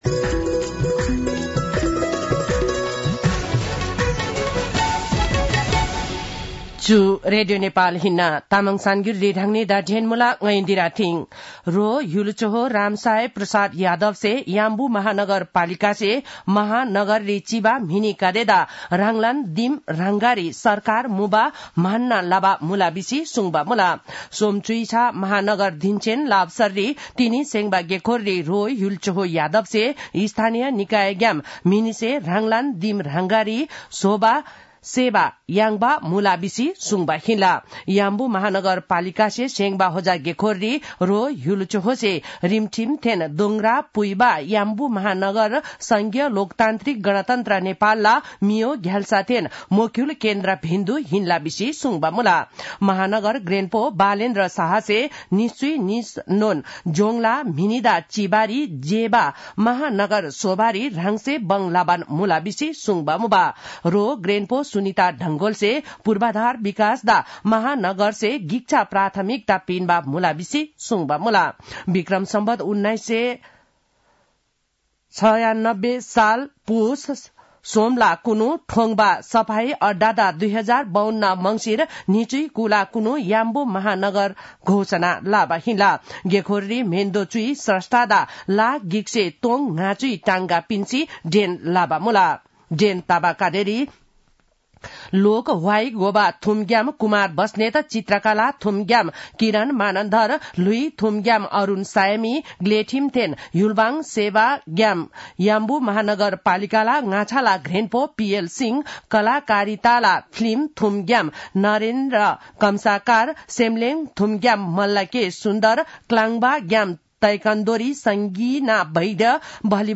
तामाङ भाषाको समाचार : ३० मंसिर , २०८१